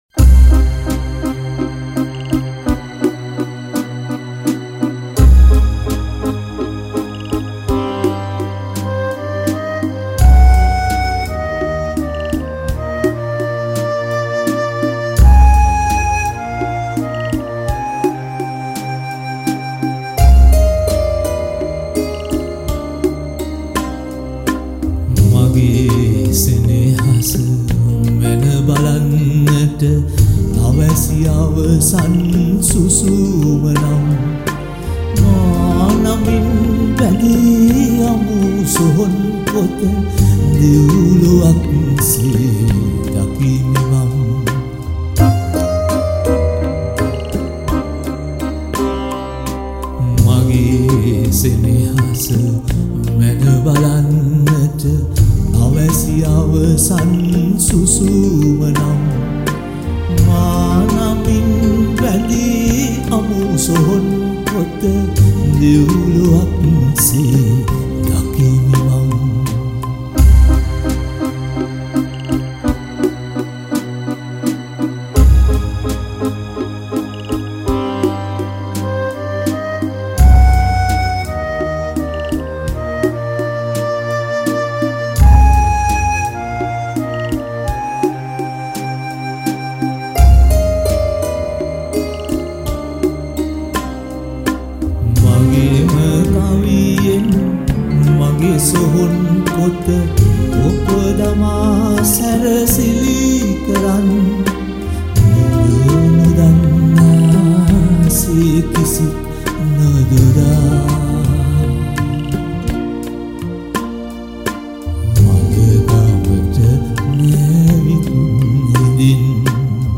All these songs were recorded (or remastered) in Australia.